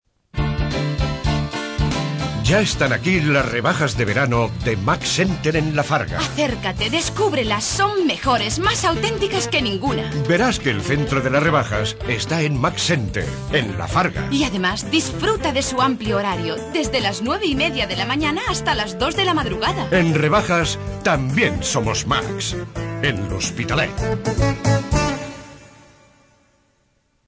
kastilisch
Sprechprobe: Werbung (Muttersprache):